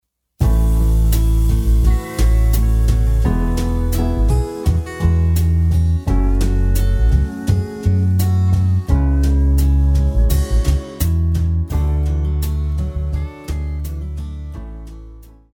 古典
次中音長號
樂團
練習曲,學習教材,古典音樂
僅伴奏
沒有主奏
沒有節拍器